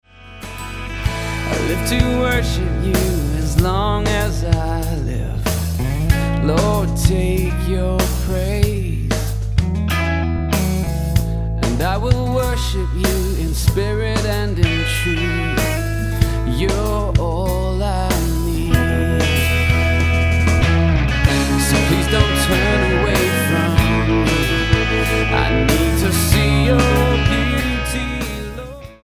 STYLE: Rock